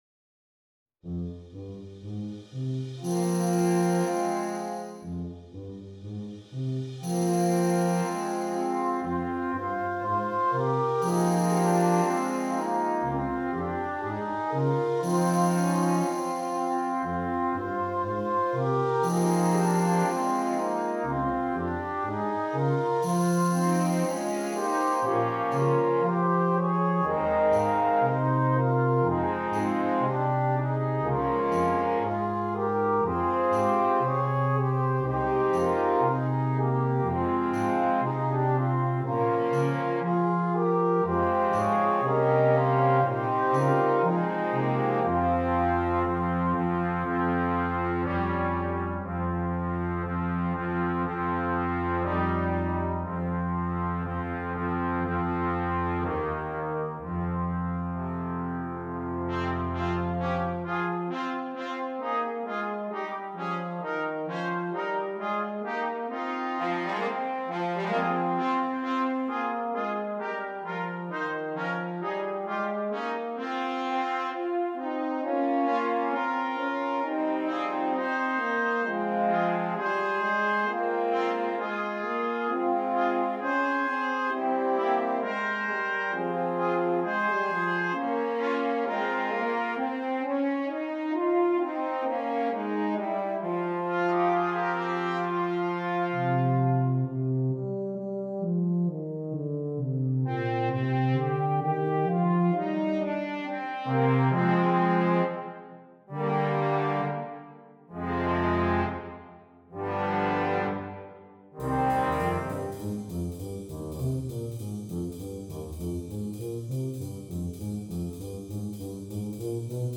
Christmas
Brass Choir (4.4.3.1.1.perc)